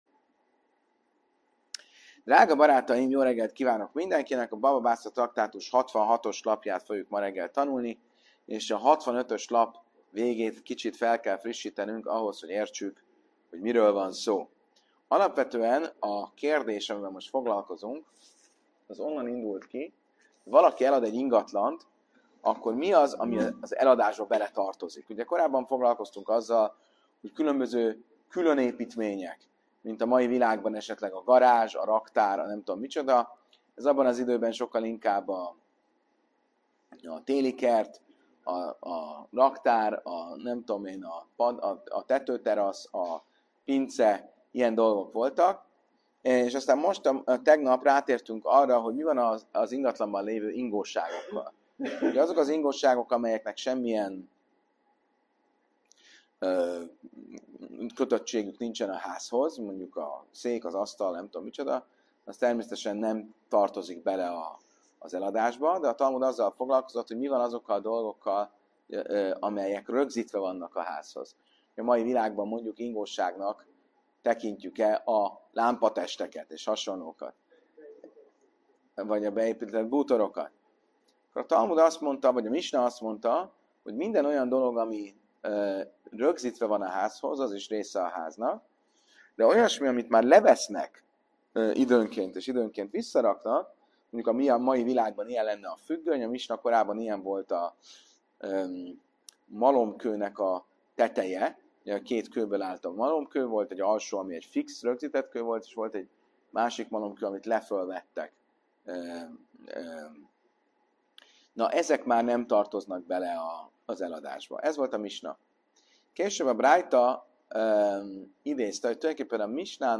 Helyszín: Óbudai Zsinagóga (1036. Budapest, Lajos u. 163.)